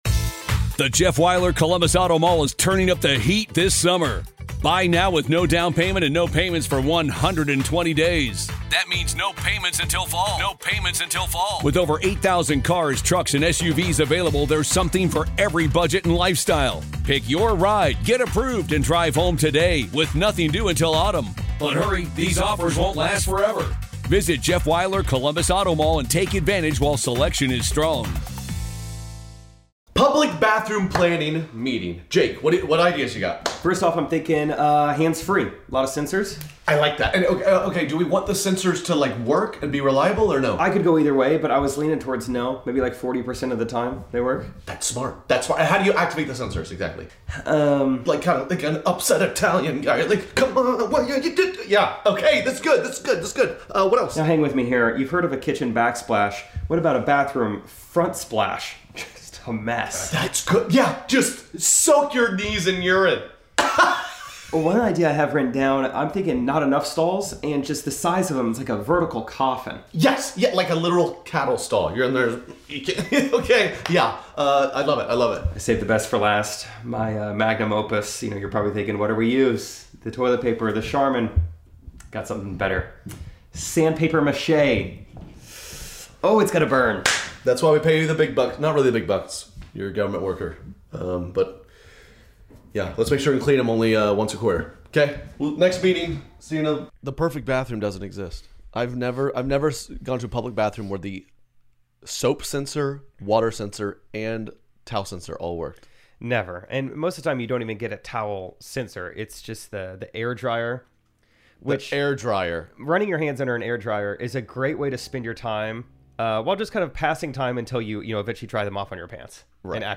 The perfect bathroom doesn't exist. We have a dramatic reading from the creepy black shirt stalker. The boys also discuss bidets.